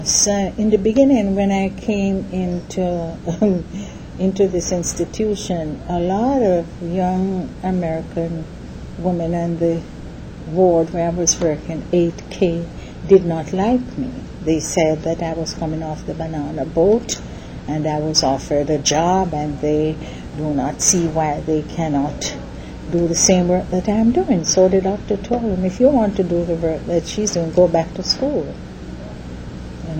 5 audio cassettes